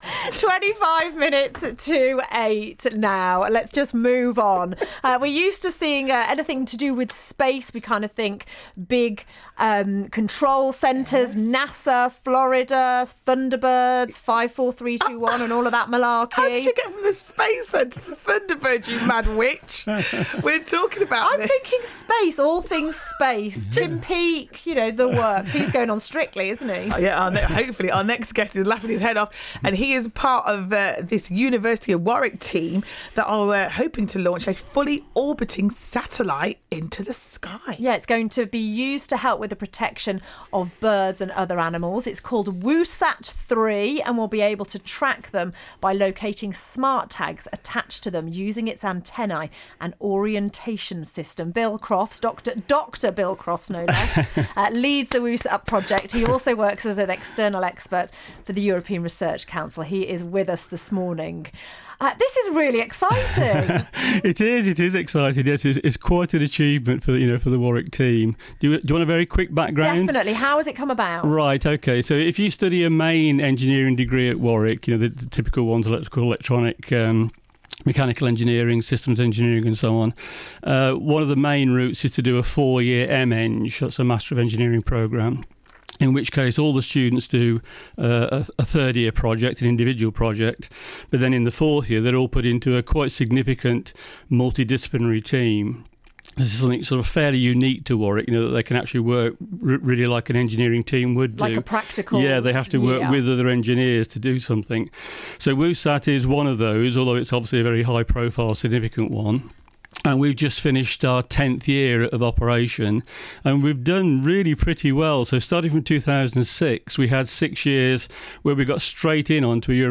BBC CWR Interview